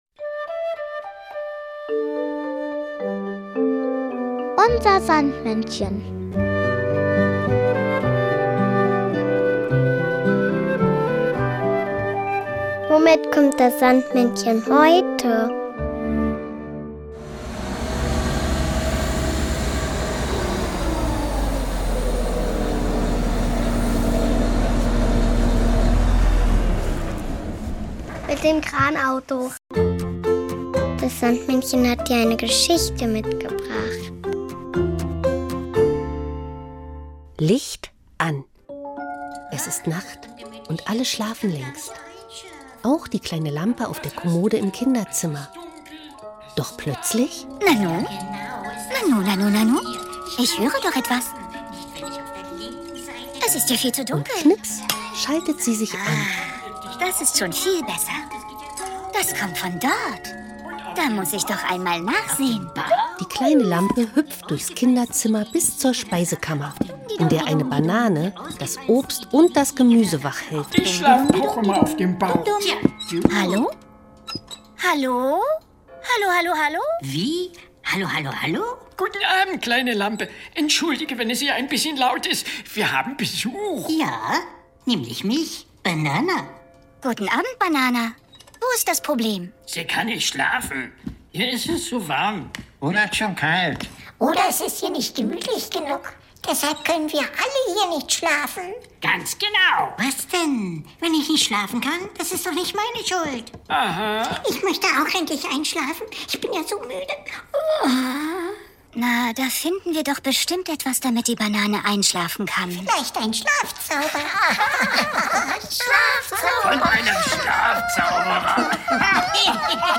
Geschichte mitgebracht, sondern auch noch das Kinderlied "Wenn der